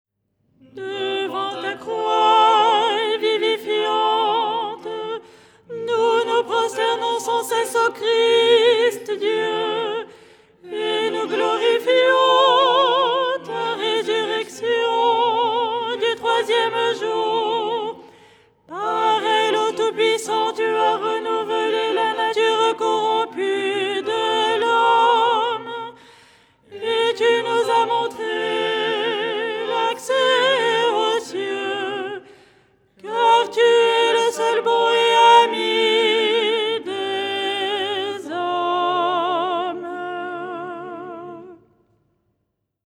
Soprano
ton4-04-soprano.mp3